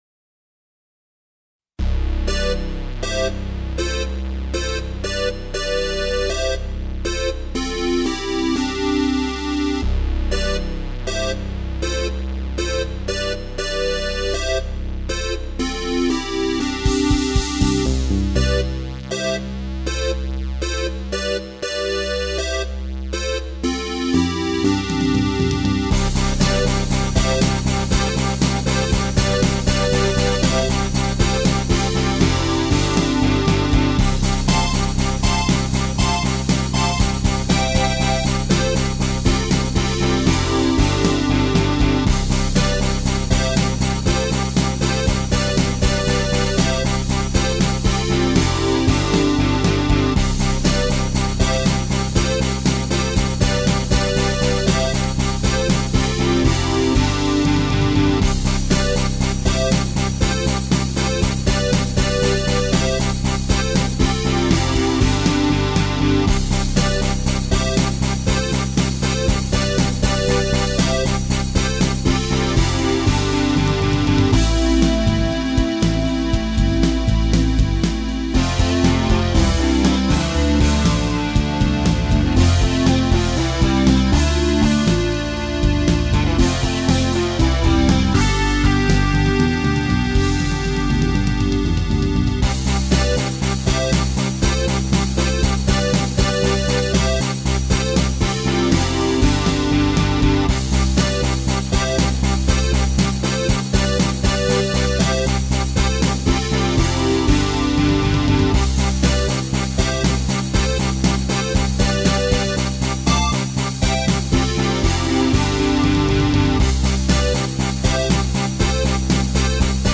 Coco MIDI rendition